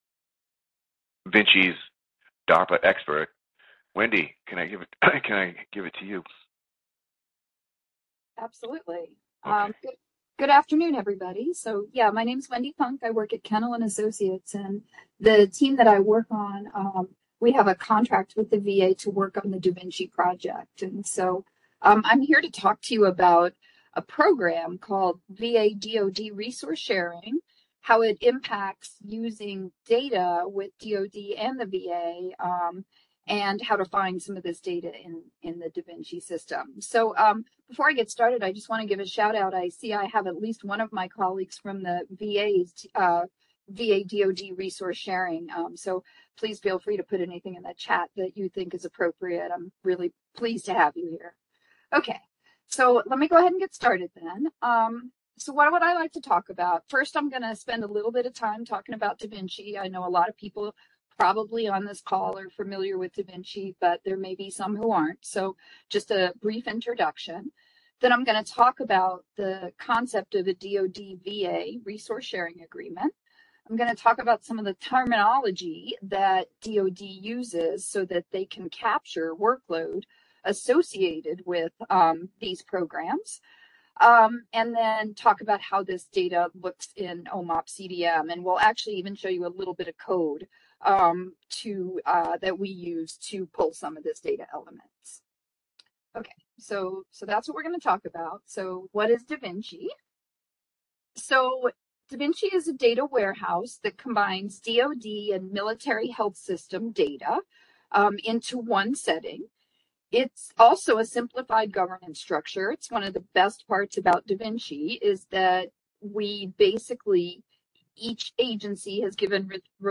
Description: This presentation will discuss VA-DoD Resource Sharing Agreements, eligible populations, and focus on how to identify care of Veterans referred to military facilities through their VA benefit.